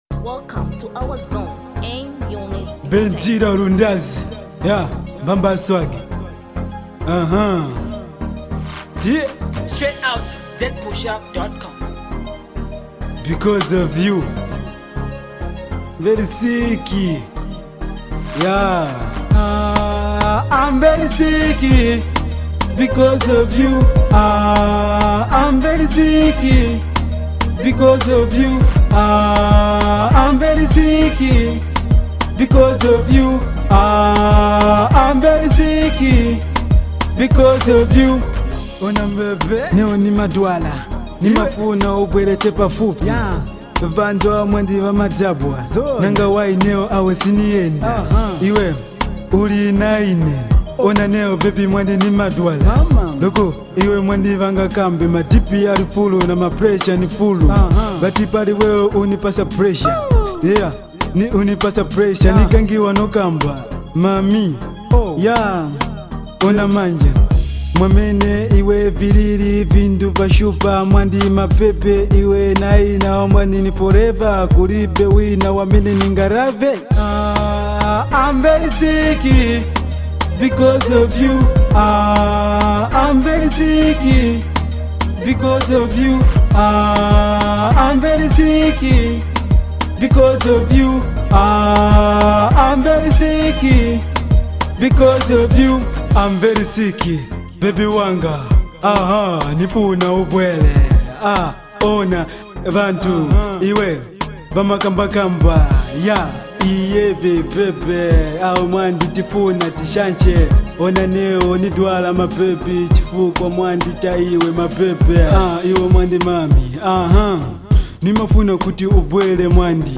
great hooked RnB track